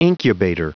Prononciation du mot incubator en anglais (fichier audio)
Prononciation du mot : incubator